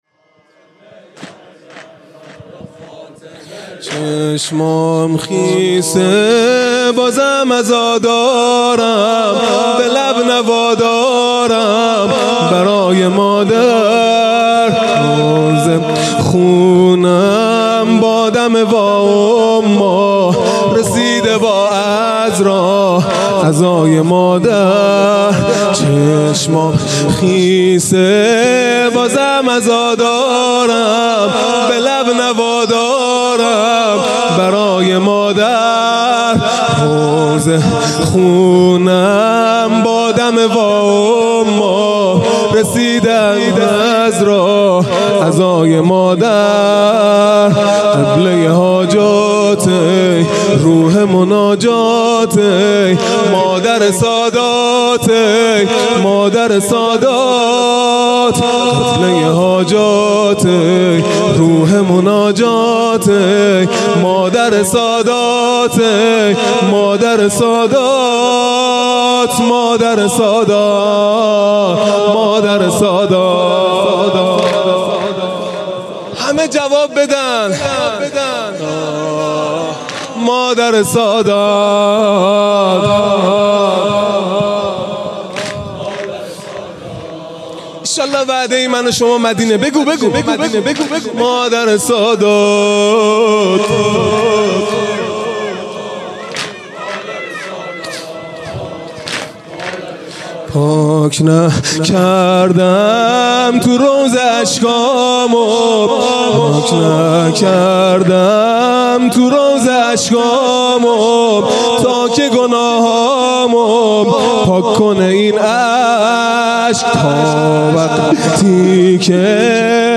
واحد | چشمام خیسه بازم عزادارم
عزاداری فاطمیه اول | شب دوم 29 دی 1397